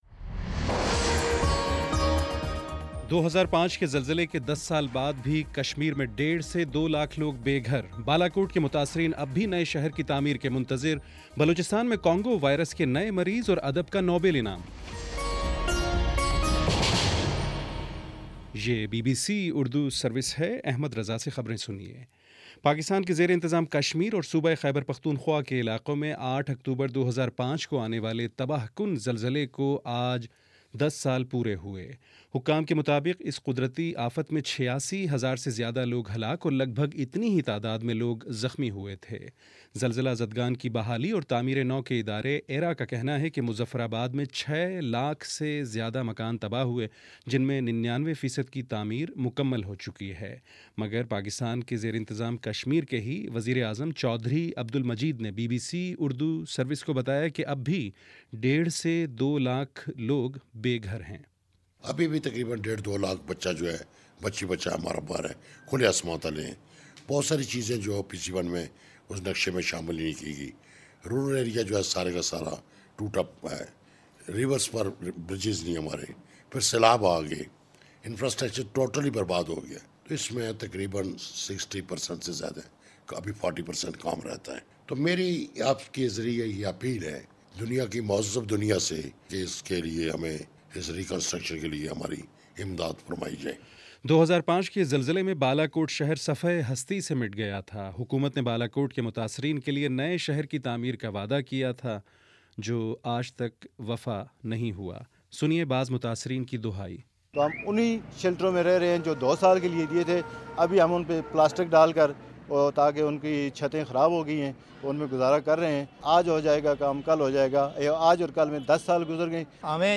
اکتوبر08: شام سات بجے کا نیوز بُلیٹن